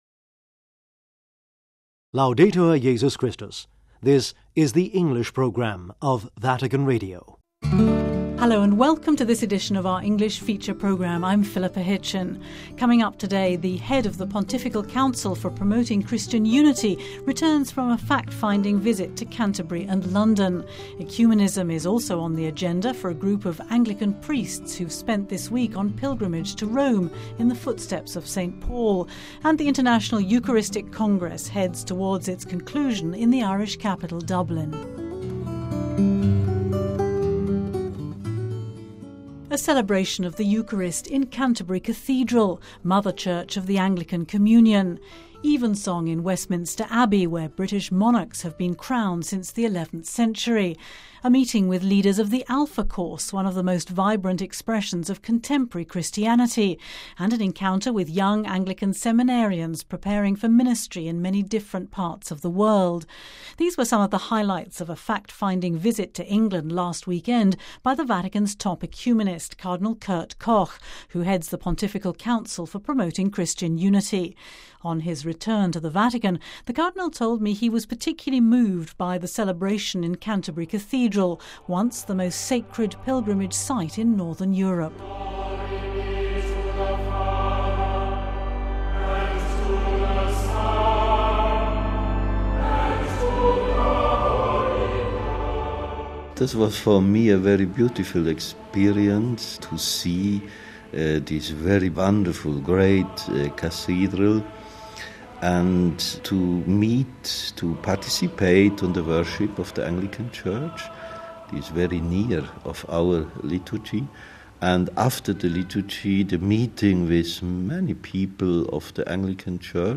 International Eucharistic Congress : Our correspondant in Dublin reports on 'a journey to Purgatory' in search of reconciliation and healing...